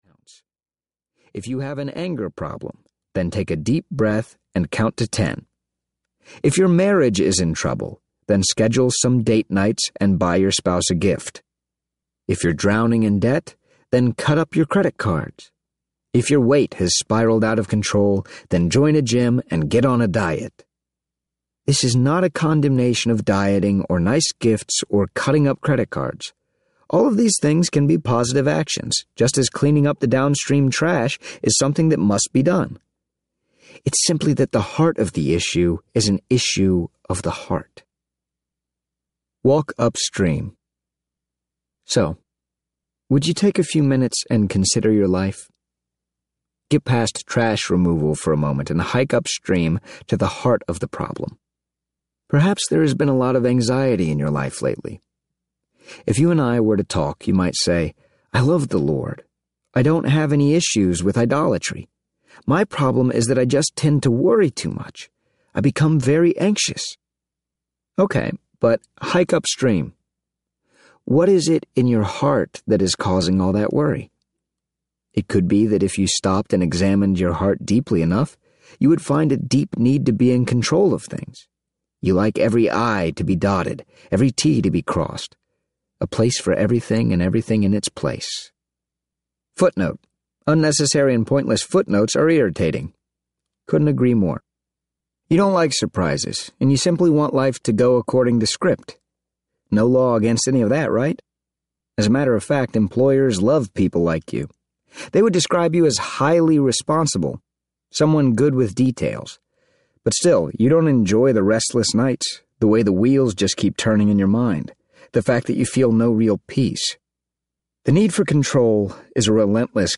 Gods at War (Student Edition) Audiobook
Narrator